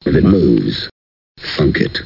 Amiga 8-bit Sampled Voice
1 channel